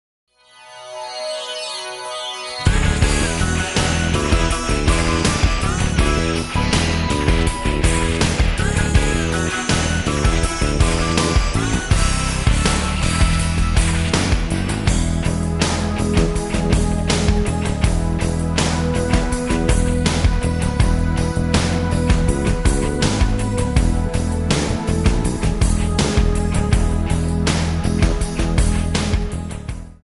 Backing track Karaoke